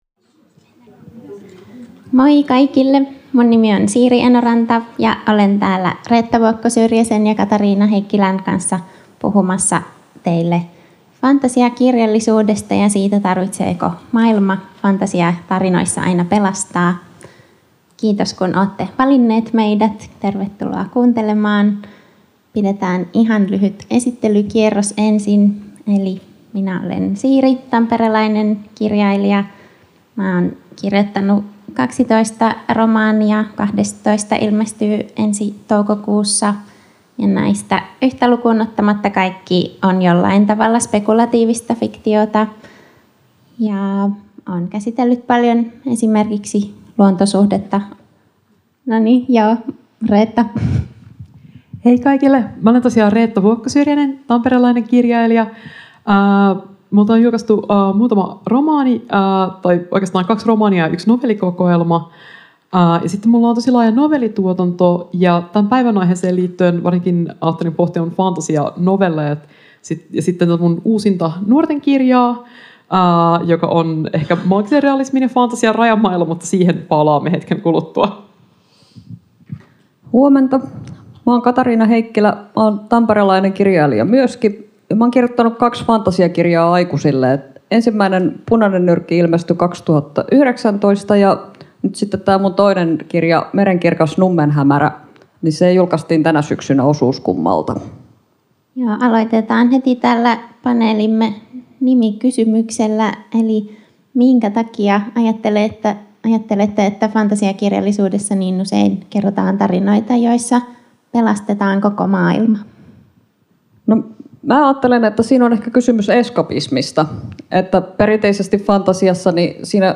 Ohjelman taltiointi: Tampereen Kirjafes